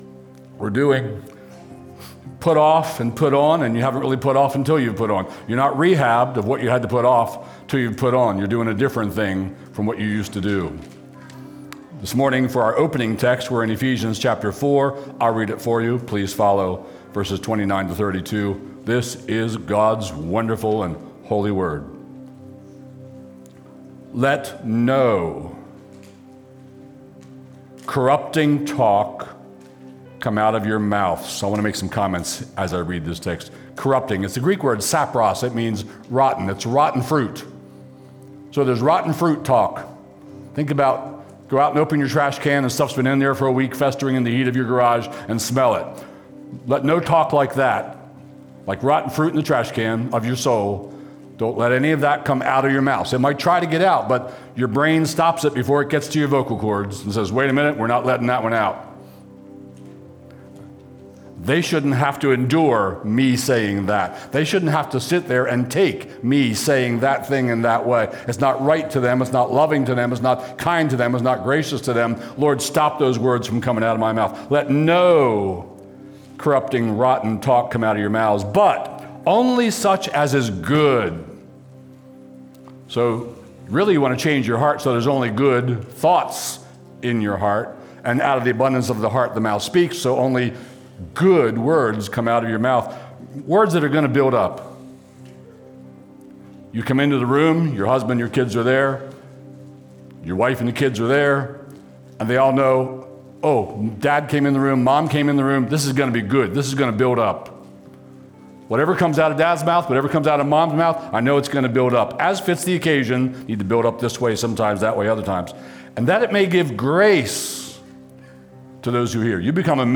Stream Sermons from Cornerstone Harford County